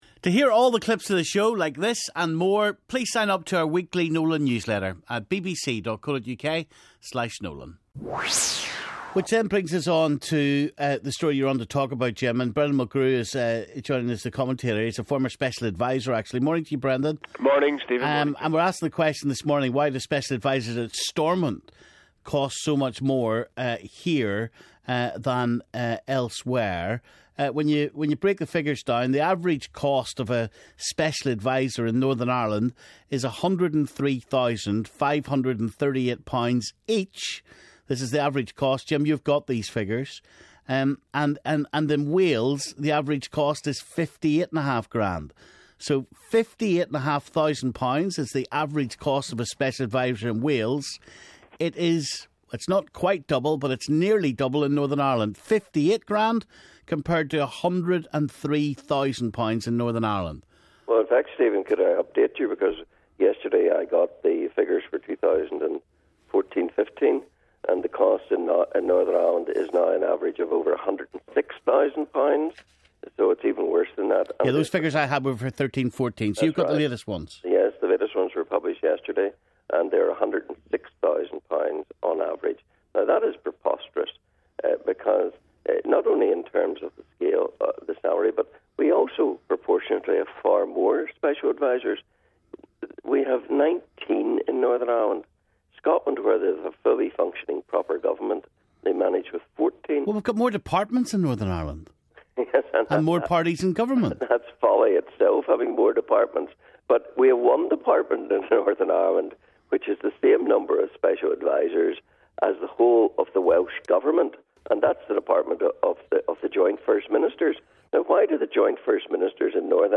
He's on the line.